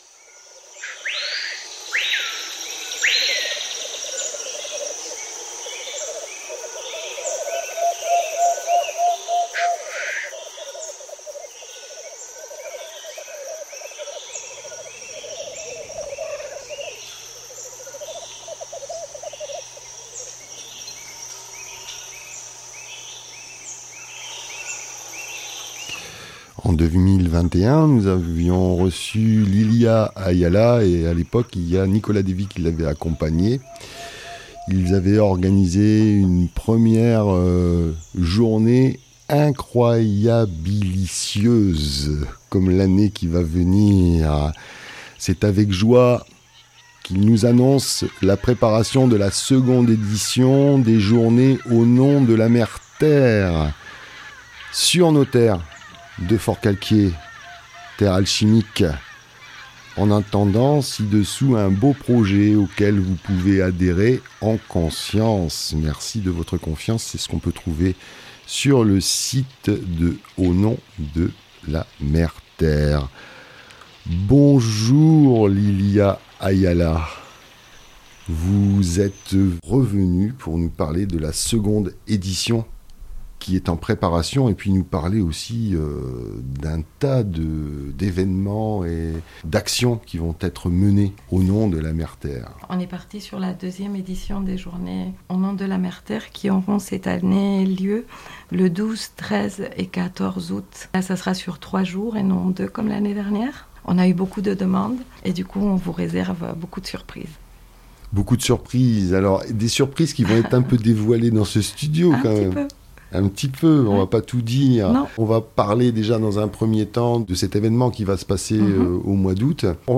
Fort du succès de la première édition en juillet 2021, qui s'est déroulée sous la forme d'un séminaire de deux jours de conférences, d'expositions et d'échanges autour de notre Mère-Terre au Couvent des Cordeliers à Forcalquier sur le thème : " Et si on réécrivait une histoire ?, la seconde édition est prévue en août prochain. Pour en savoir plus, il vous suffit d'écouter cette belle entrevue.